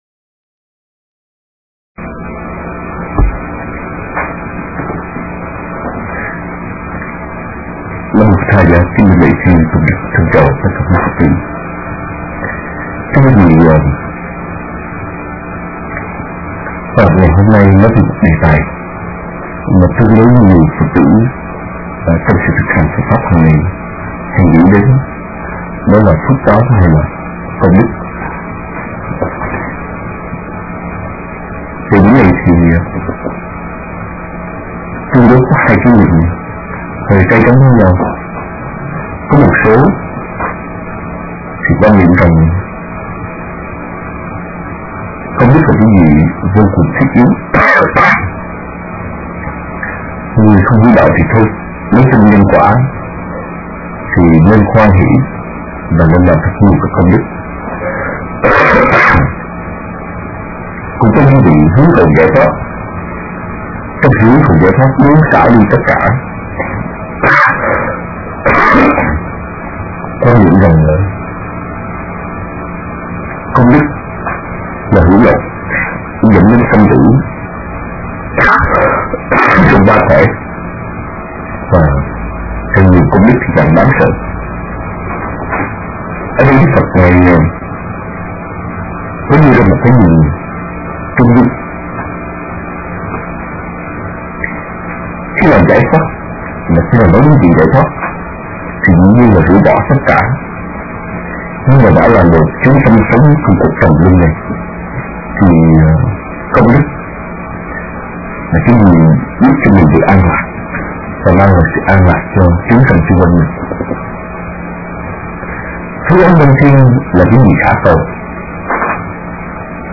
giảng trong rơom Diệu Pháp